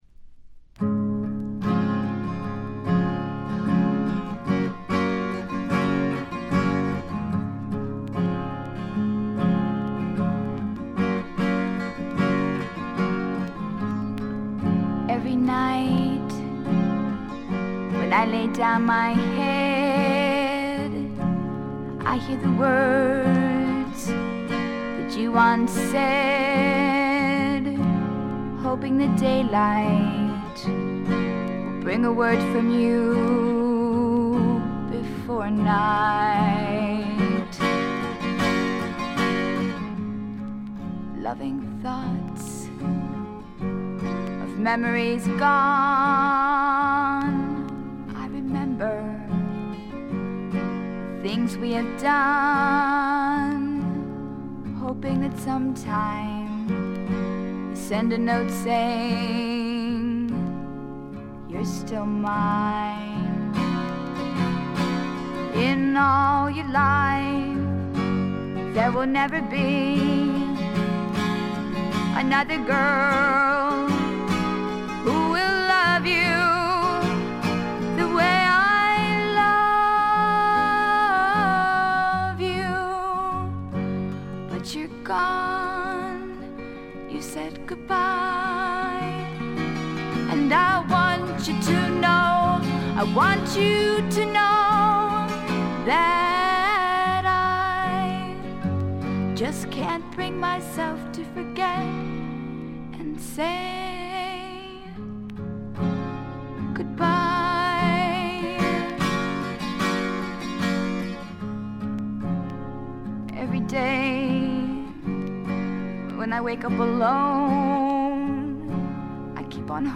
全曲自作の素晴らしい楽曲、清楚な歌声、美しいアコギの音色、60年代気分を残しているバックの演奏、たなびくフルートの音色。
試聴曲は現品からの取り込み音源です。